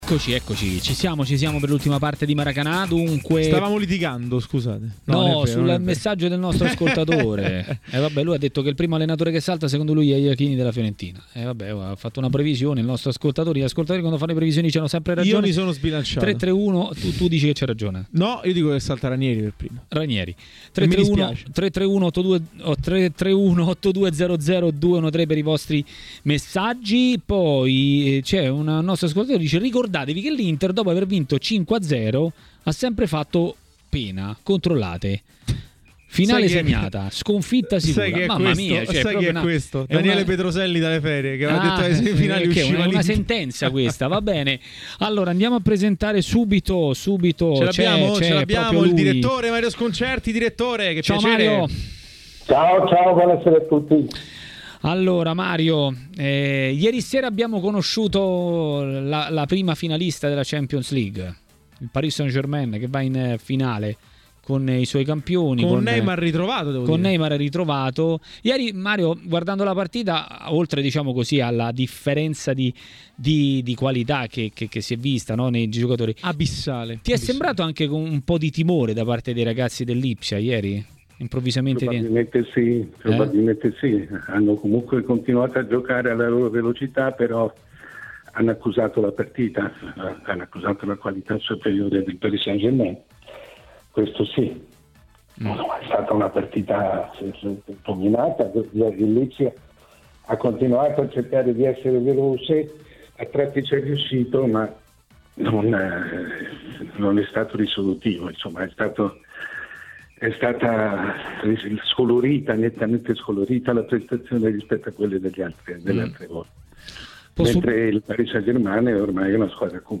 Ai microfoni di TMW Radio è intervenuto il direttore Mario Sconcerti, durante Maracanà, per parlare dell'attualità calcistica e per commentare inizialmente il passaggio del turno del PSG.